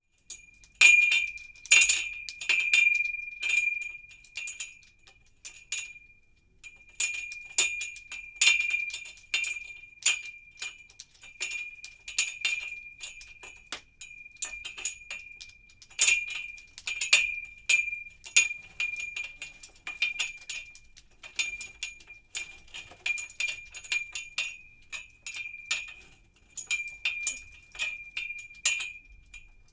Golpes metálicos en el mástil de un barco